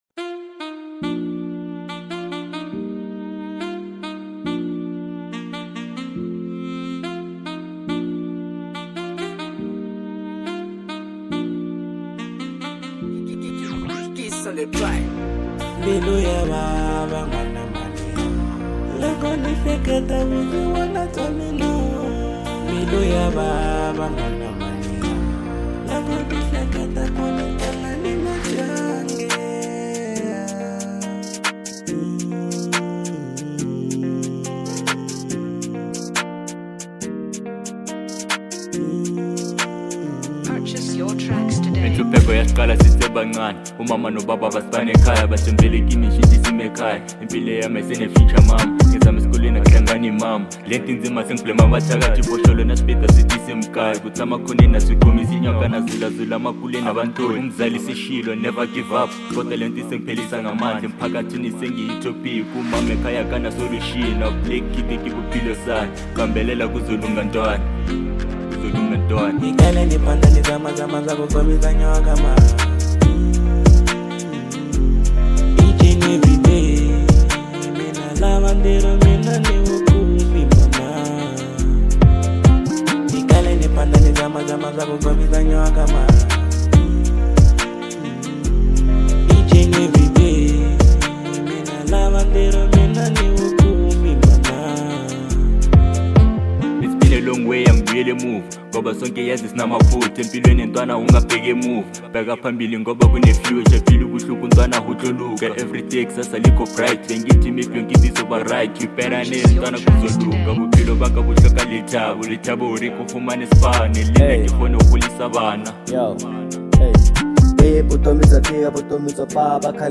03:00 Genre : Trap Size